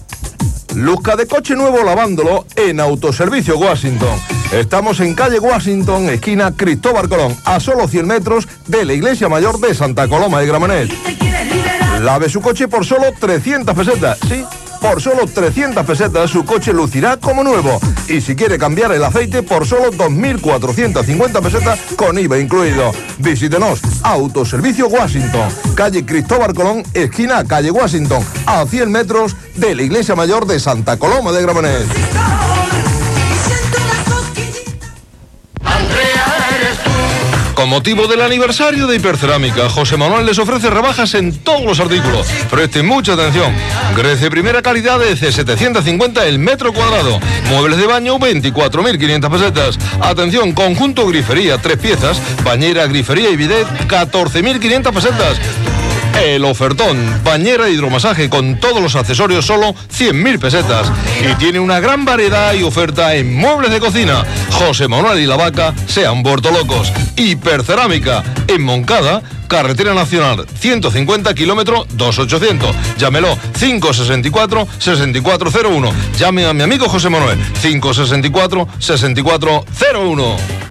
Bloc publicitari